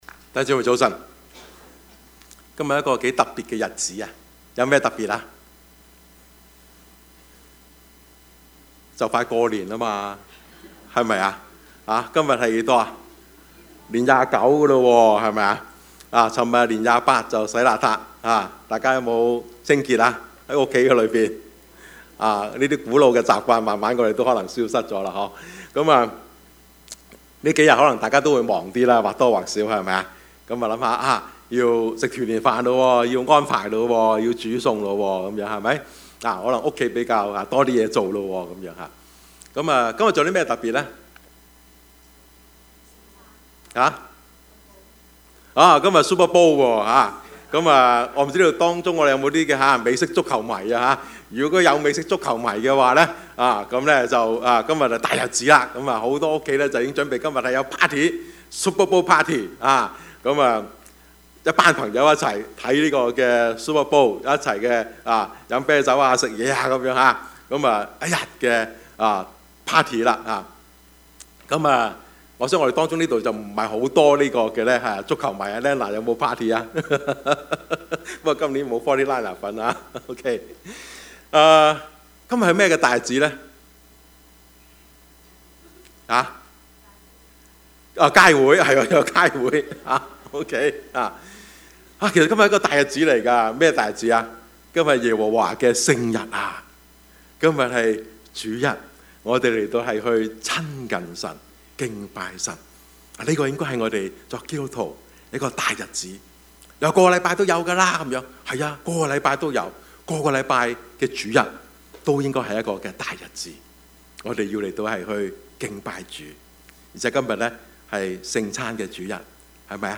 Service Type: 主日崇拜
Topics: 主日證道 « 信是實在 英雄與懦夫 »